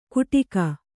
♪ kuṭika